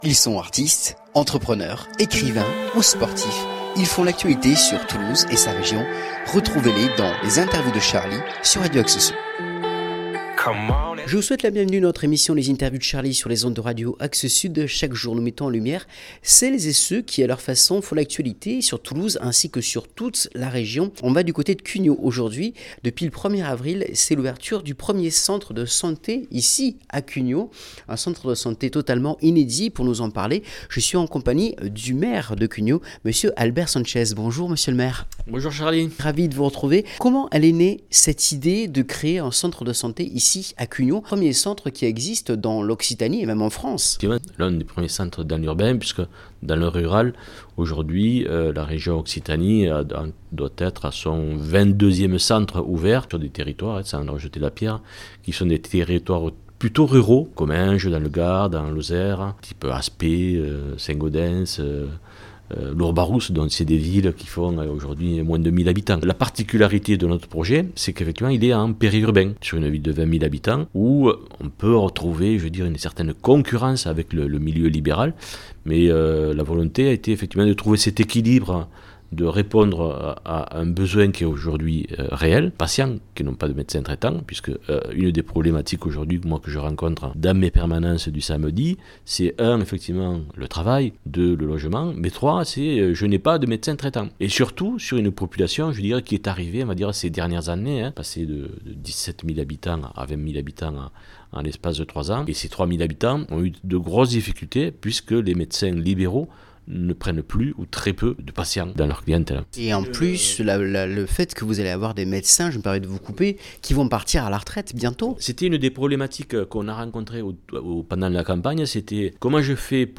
Interview exclusive d’Albert Sanchez, Maire de Cugnaux, qui, de concert avec l’Agence Régionale de Santé et la Région Occitanie a fait ouvrir un centre de santé dans sa commune pour pallier au manque de médecins et au futur départ en retraite de médecins qui officient sur Cugnaux.
Albert-Sanchez-Centre-Sante-Cugnaux.mp3